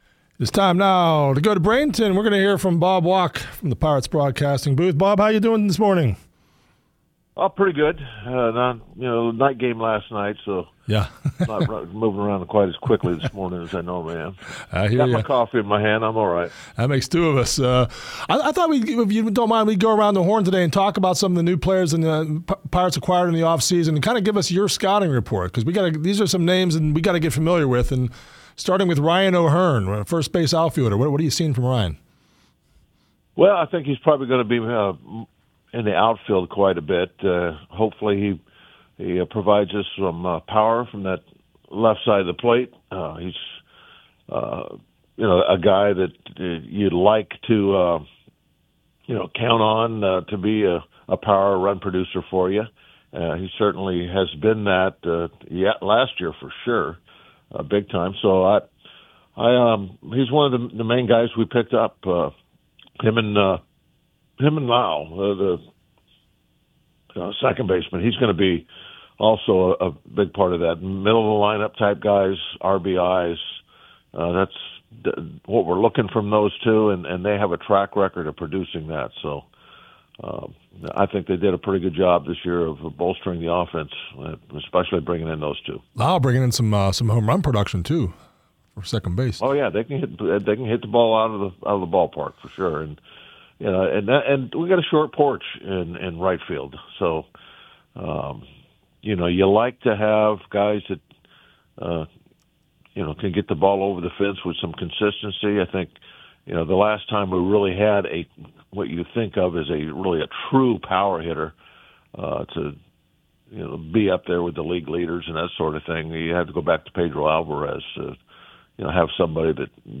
If you missed Walk’s live interview, no worries: Here it is: